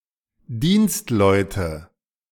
A Dienstmann (German: [ˈdiːnstˌman] plural: Dienstleute, German: [ˈdiːnstˌlɔɪ̯tə]